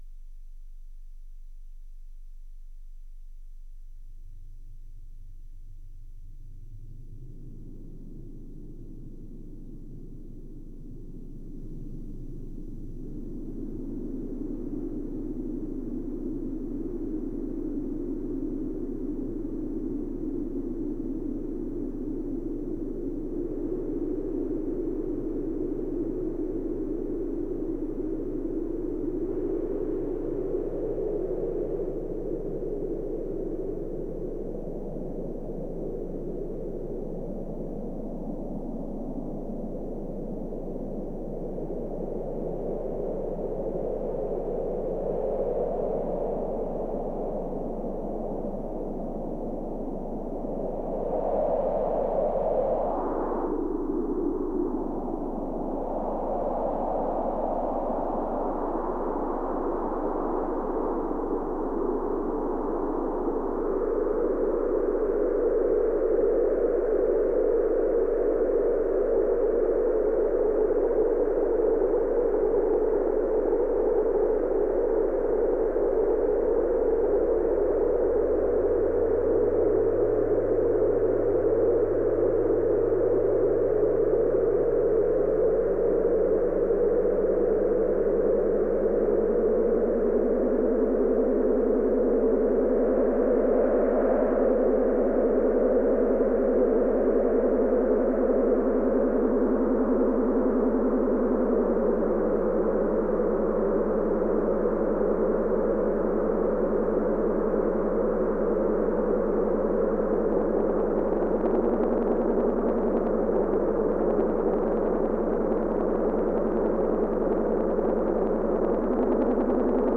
Plusieurs voix sur A100, Model-D au ruban, 2600.